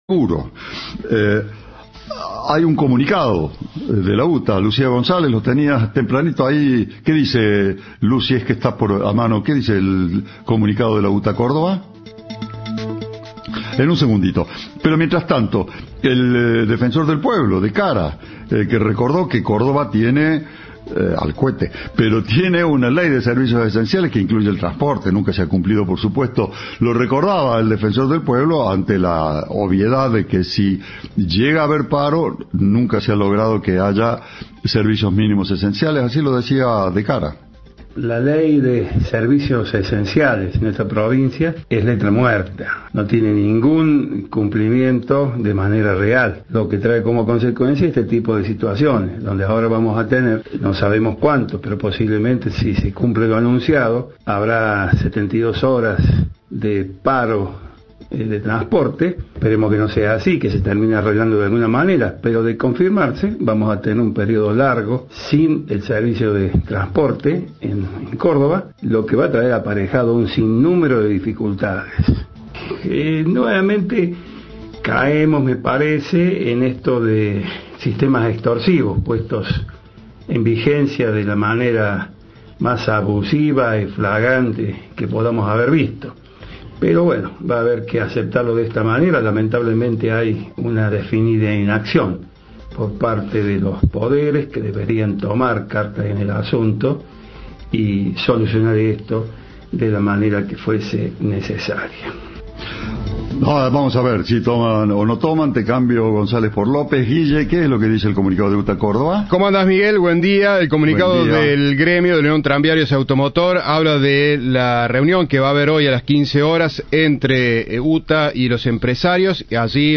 El defensor del Pueblo de Córdoba dijo a Cadena 3: "Nuevamente caemos en sistemas extorsivos puestos en vigencia de la manera más abusiva".  UTA lanzó un paro por 72 horas.
Informe